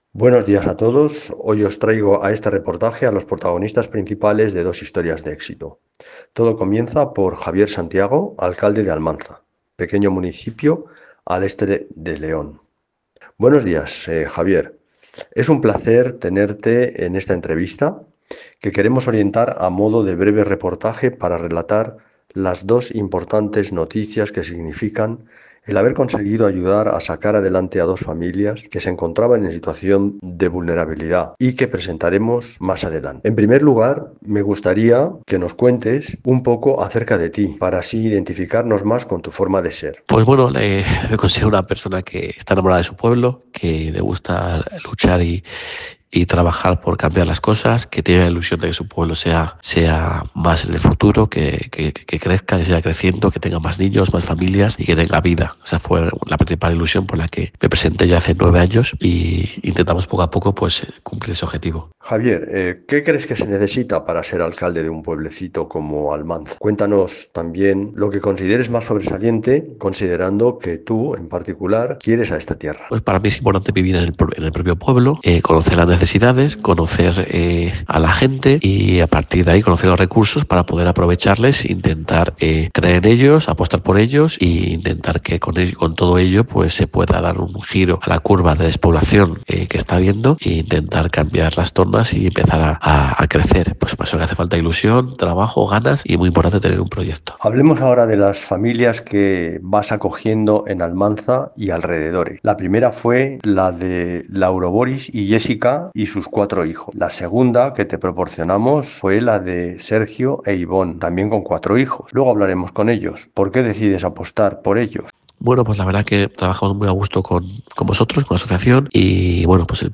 Entrevista a Javier Santiago, alcalde de Almanza
Audio de la entrevista
Protagonista: Javier Santiago, alcalde de Almanza (León)